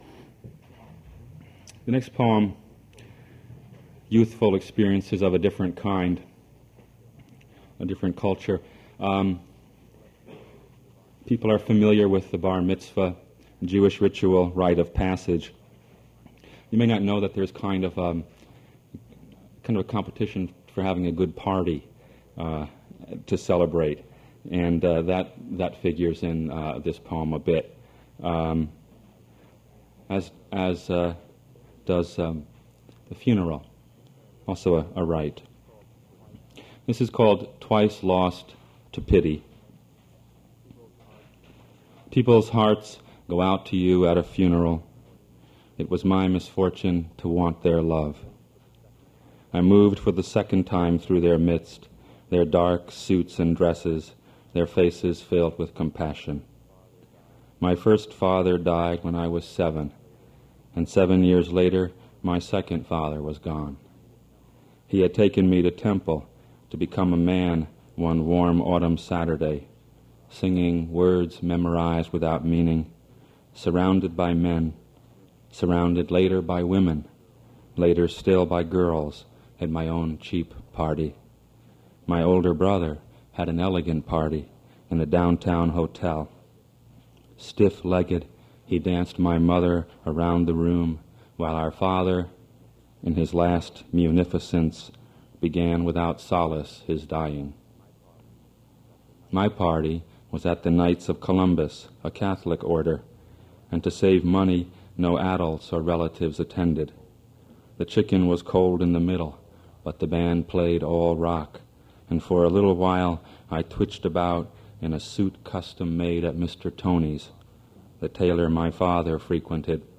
poetry reading at Duff's Restaurant.
generated from original audio cassette.
Note the recording starts in the middle of the reading